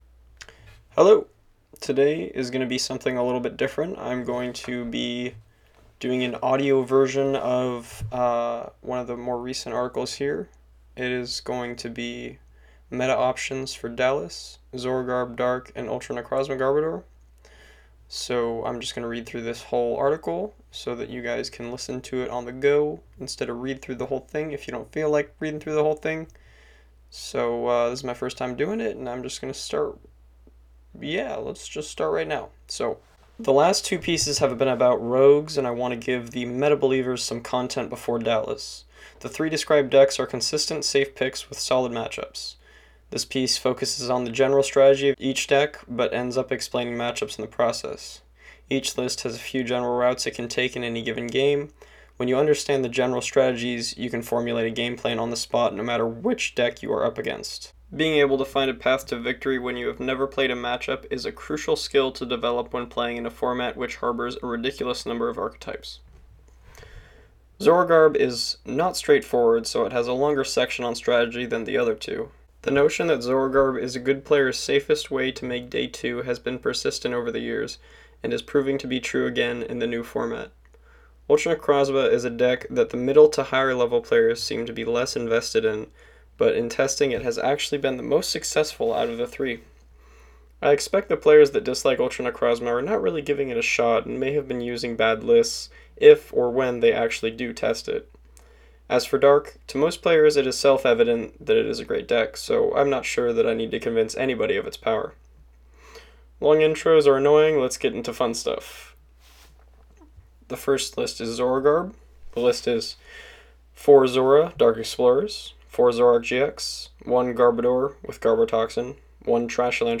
Above is an audio recording of the article.